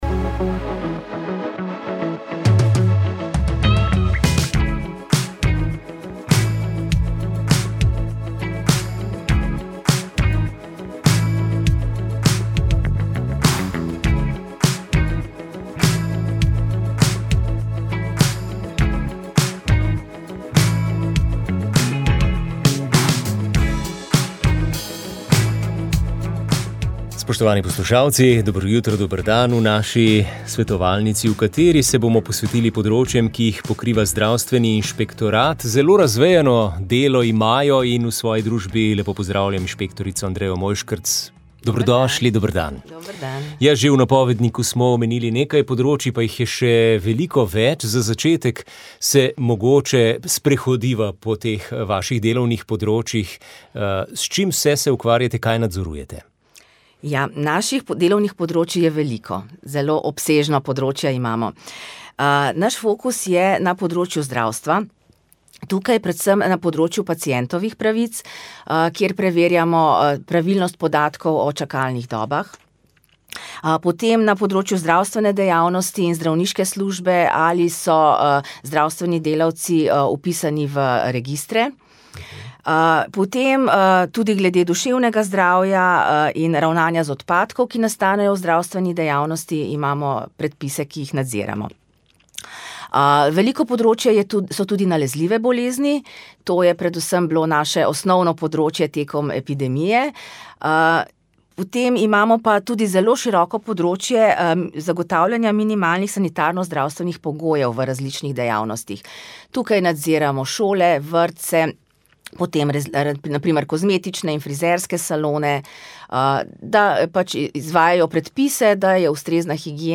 Kviz, zabavno tekmovanje med mladostjo in zrelostjo, klepet, glasba za različne generacije in dva voditelja